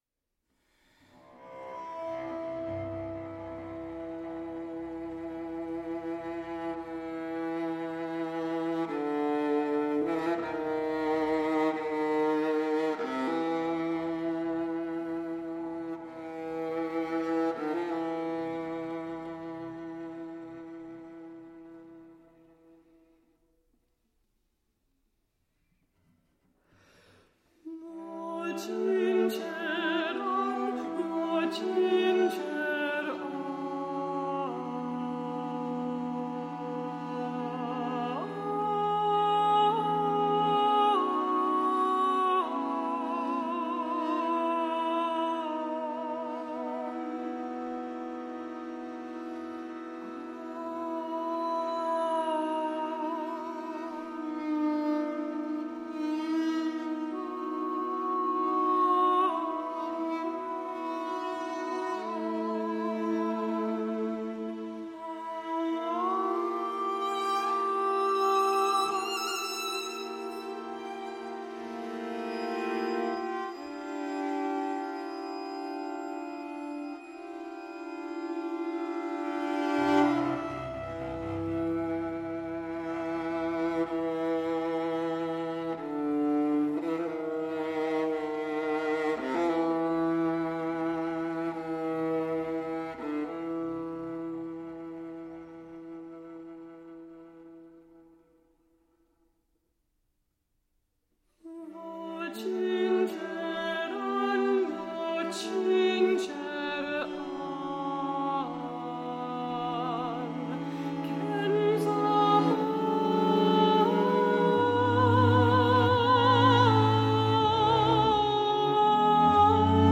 mezzo-soprano
viola